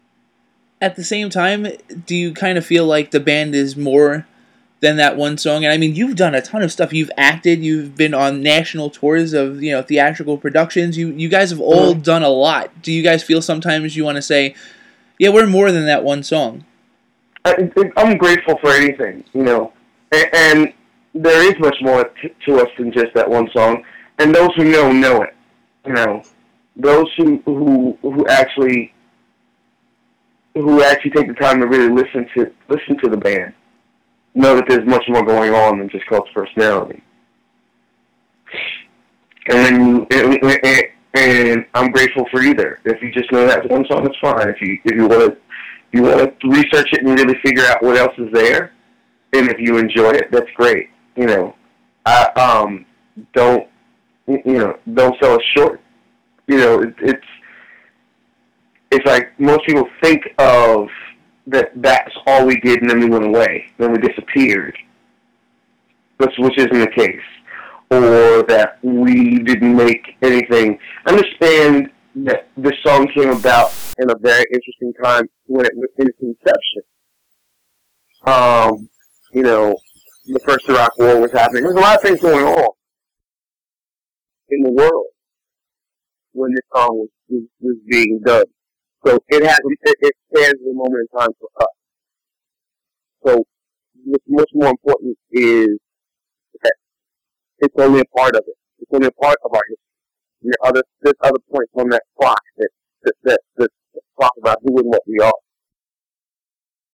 Review Fix chats with Living Colour Frontman Cory Glover who talks about the success of the band’s signature song and how while they are grateful for the success it has brought them, it’s not exactly everything that they are capable of.